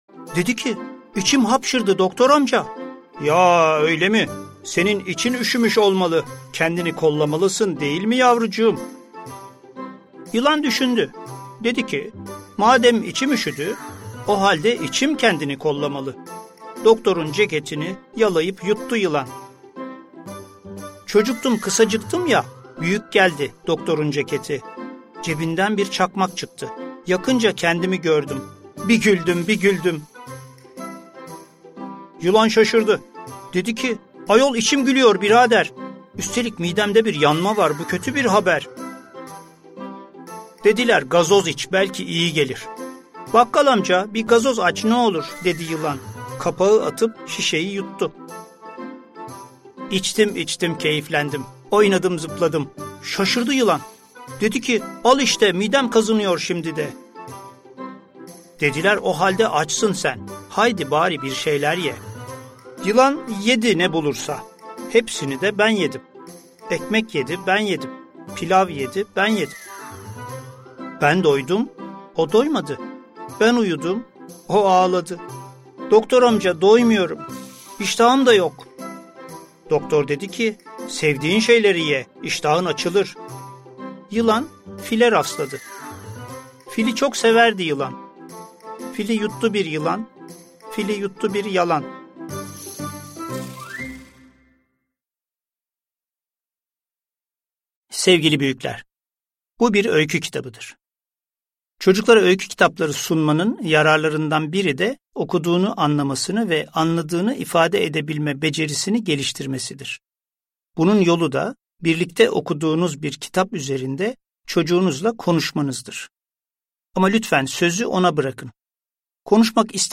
Kısa, uyaklı ve ritmik cümle yapıları, belletici tekrarlar, dil ve zekâ kıvraklığını kazandıracak söz oyunları ve tekerlemelerle anlatılan eğlenceli öyküler henüz okumayı öğrenmemiş çocukları bile ”okur” hale getirmektedir.